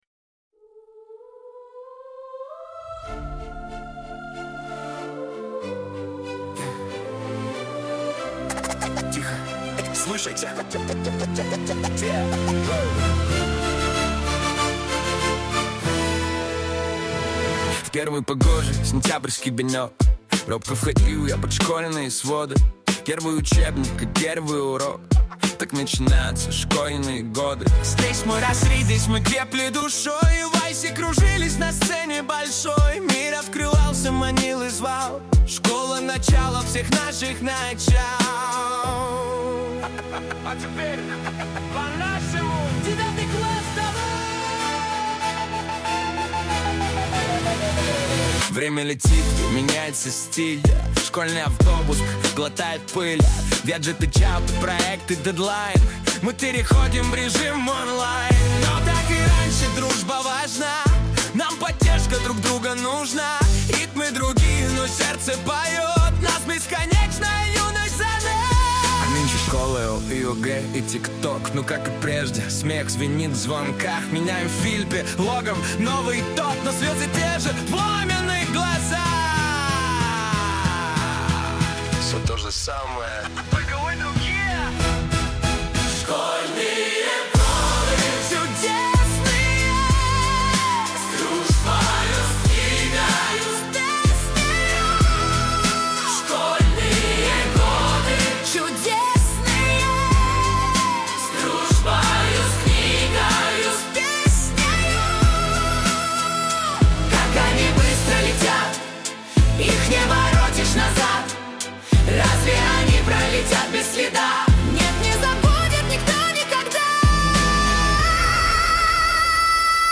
Это сложная композиция-трансформер.
🎻 Вайб: Кино + Современность